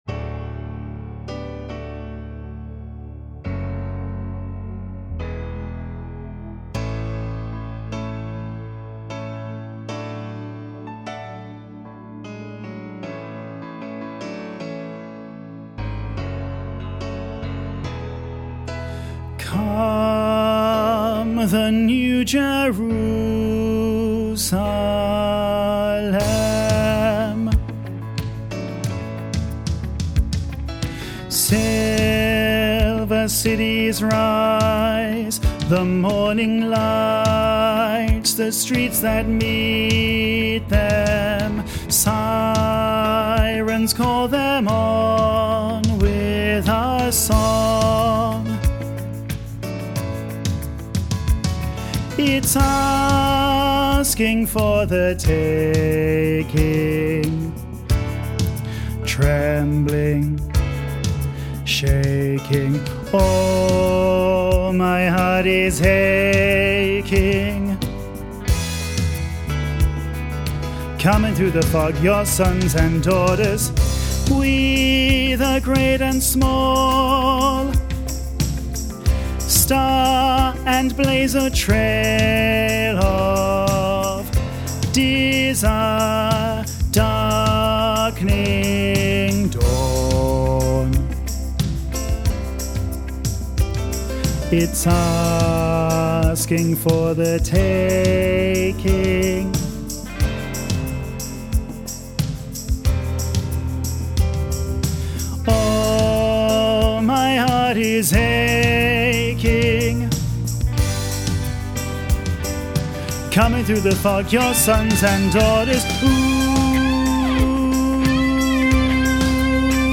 let-the-river-run-bass.mp3